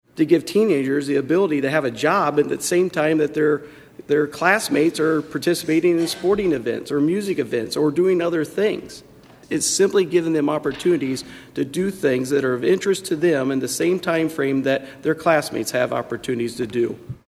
Senator Adrian Dickey, a Republican from Packwood, guided the bill through the Senate Workforce Committee.